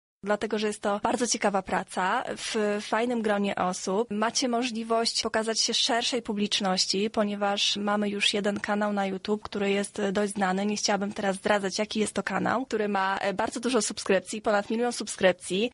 O tym dlaczego warto podjąć taka pracę mówi jedna z koordynatorek projektu